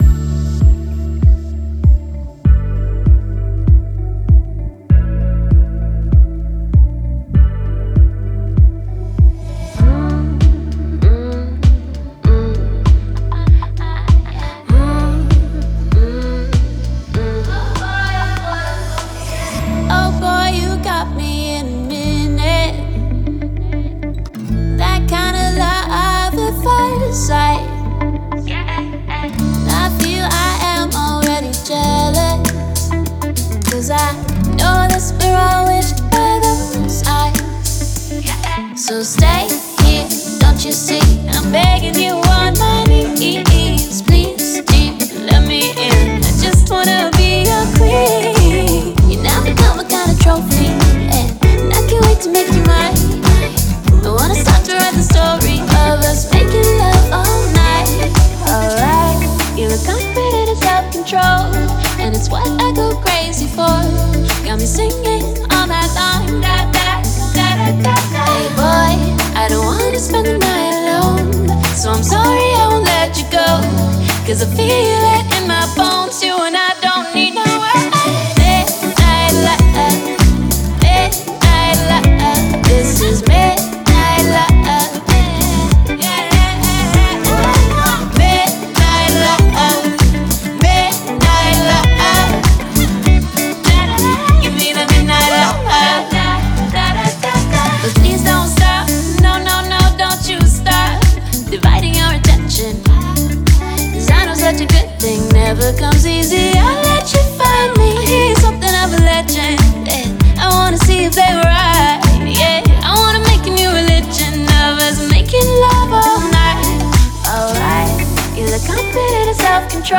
вокалистки